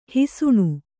♪ hisuṇu